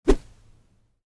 sfx_Attack.mp3